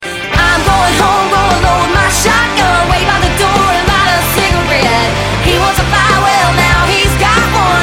shotgun_24756.mp3